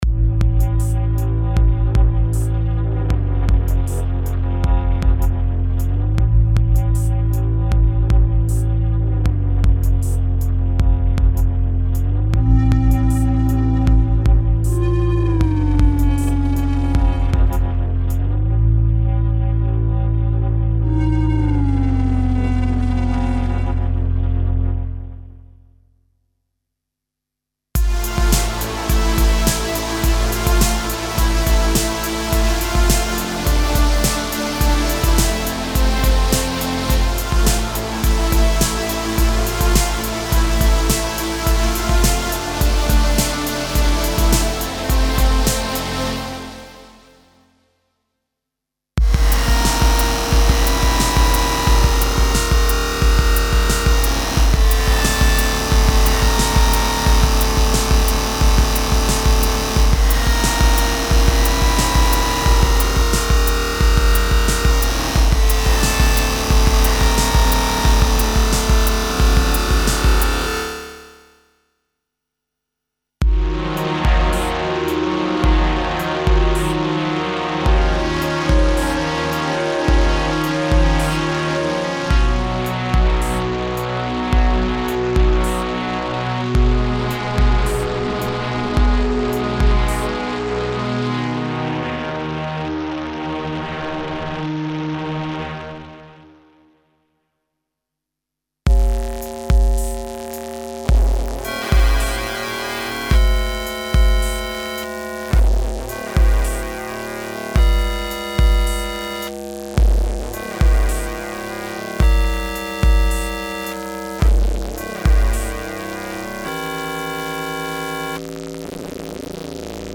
Raw digital and gritty synth pads with extreme modulations (e.g. filter, pitch, shaper, distortion, etc.) and lo-fi feeling for hard electronica fans.
Info: All original K:Works sound programs use internal Kurzweil K2661 ROM samples exclusively, there are no external samples used.